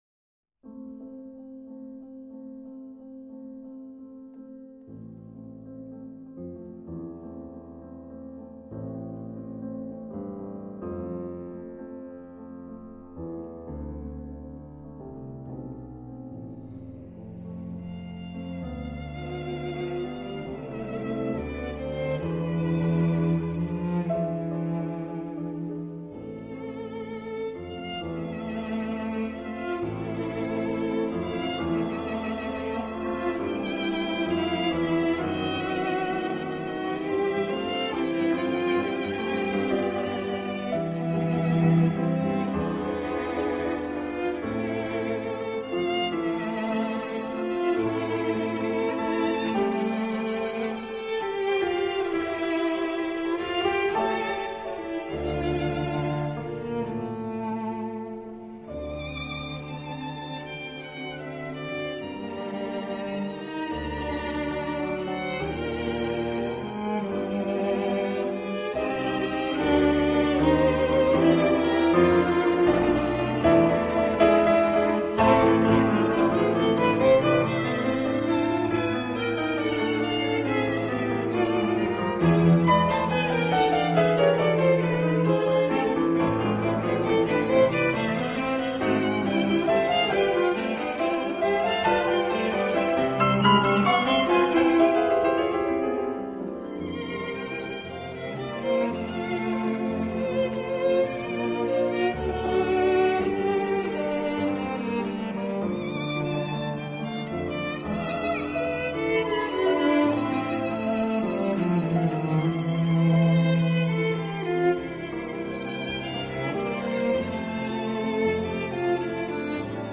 Густав_Малер_-_Квартет_для_фортепиано_и_струн
Gustav_Maler___Kvartet_dlya_fortepiano_i_strun.mp3